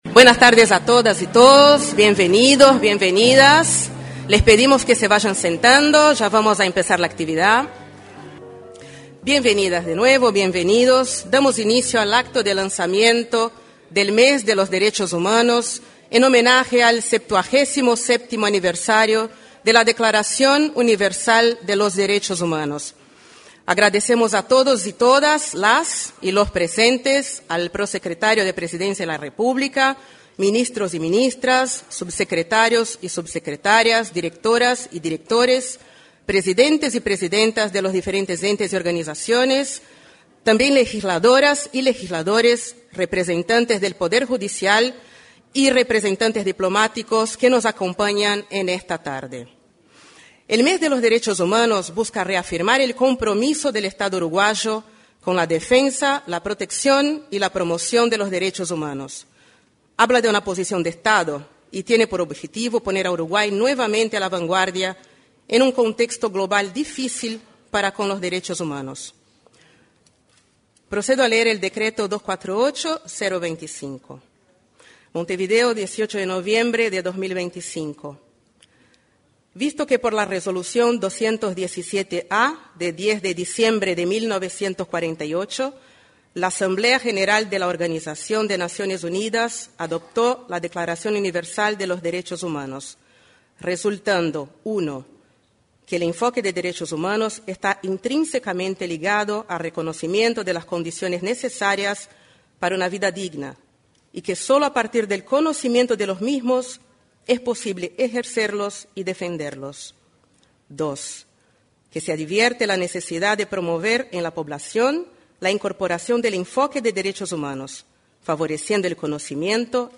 En la Torre Ejecutiva se realizó el lanzamiento del Mes de los Derechos Humanos.